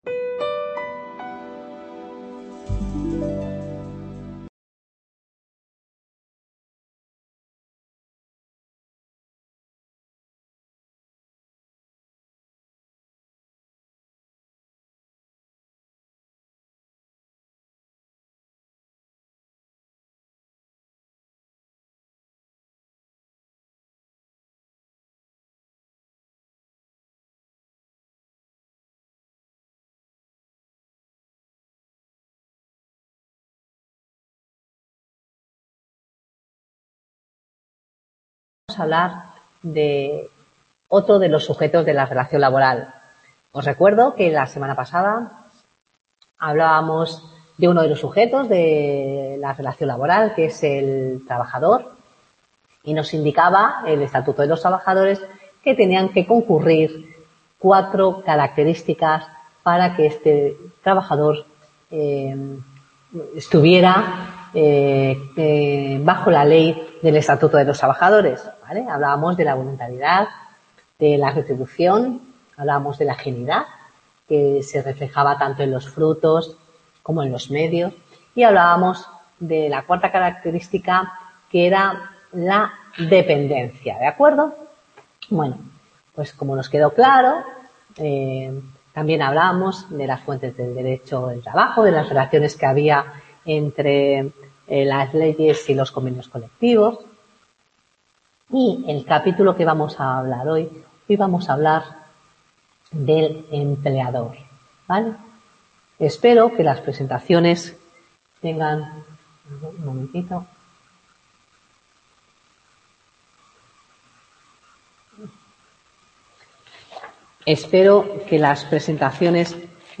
TUTORÍA 2